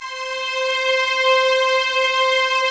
Index of /90_sSampleCDs/Optical Media International - Sonic Images Library/SI1_Swell String/SI1_Mello Swell